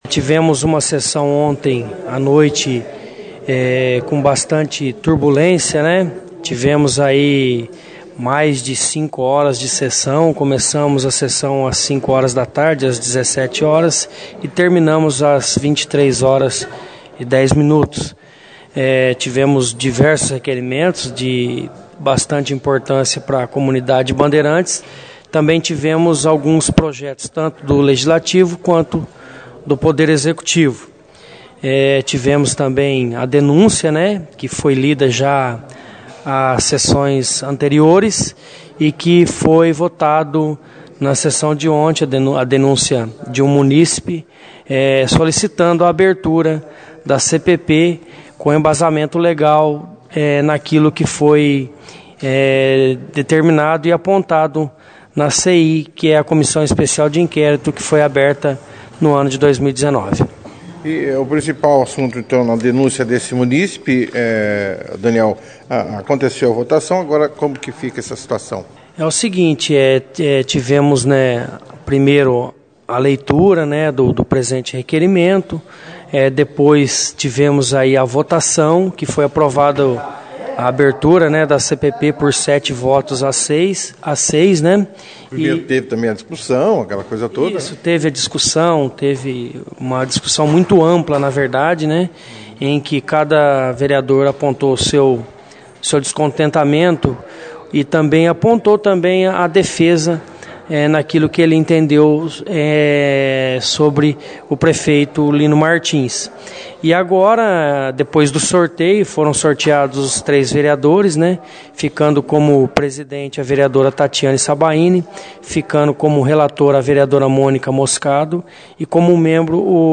A sessão foi estaque da 2ª edição do jornal Operação Cidade desta terça-feira, 03/03, com a  presidente da casa Daniel Gustavo Silva, (Babão), que detalhou os tramites desta comissão e os prazos que pertinentes a ela.